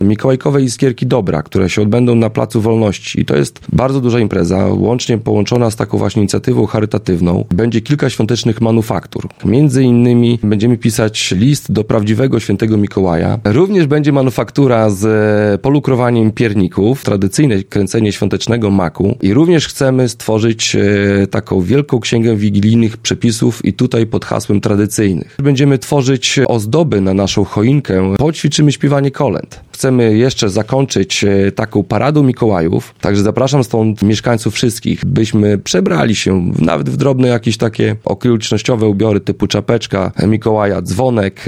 O szczegółach mówi Karol Sobczak, burmistrz Olecka.